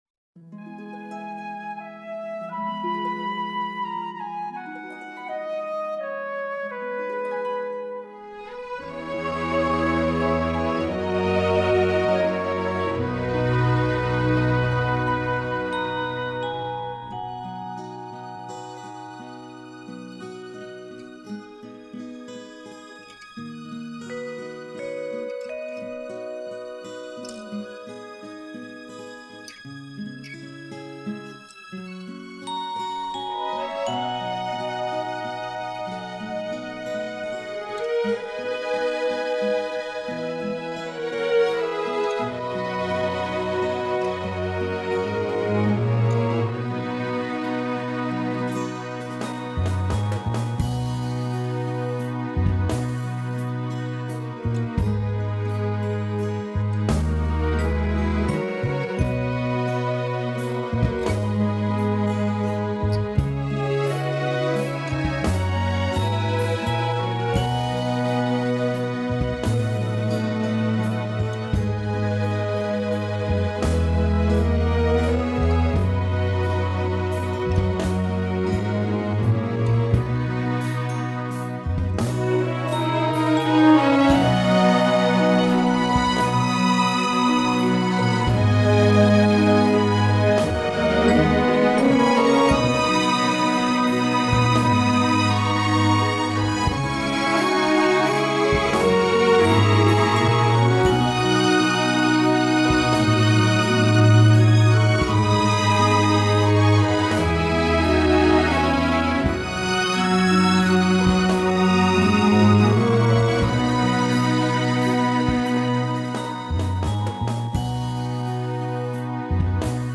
至於瑜伽之說，這首歌音域不高不低，速度不快不慢，句尾全是開口音，極適合練發聲， 氣息，和吐字。